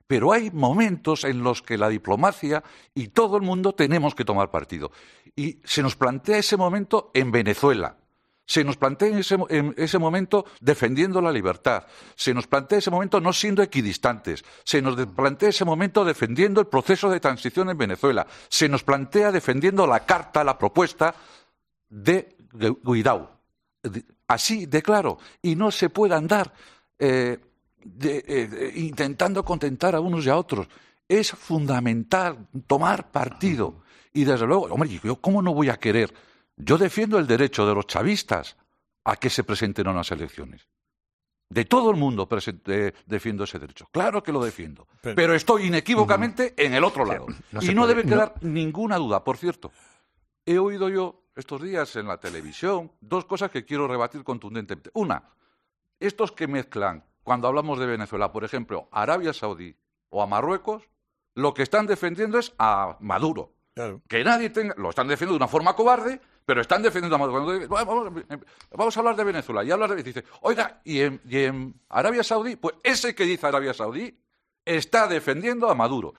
El colaborador de 'Herrera en COPE', Nicolás Redondo, han lanzado una indirecta al presentador del programa de La Sexta.